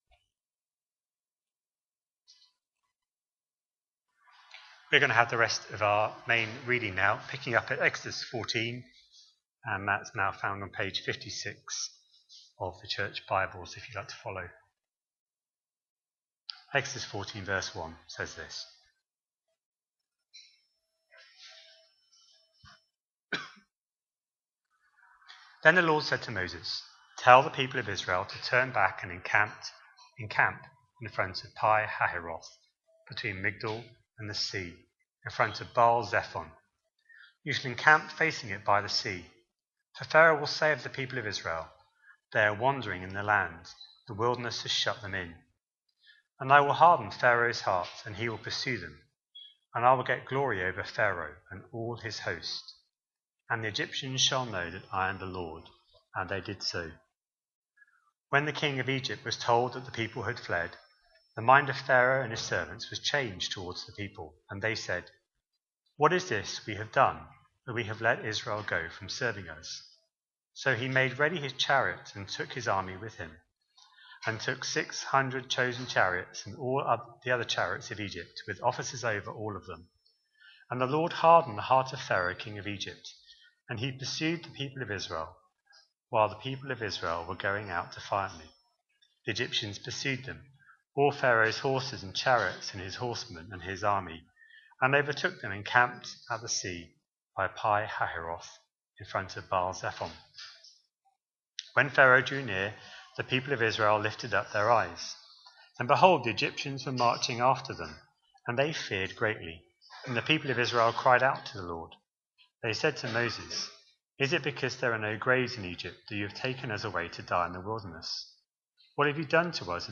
A sermon preached on 17th August, 2025, as part of our Exodus series.